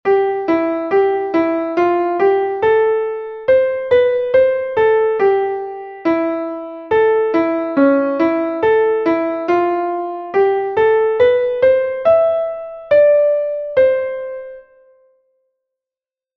Einstimmige Melodie im Violinschlüssel, C-Dur, 2/4-Takt, mit der 1. Strophe des Liedtextes.
leise-zieht-durch-mein-gemuet_klavier_melodiemeister.mp3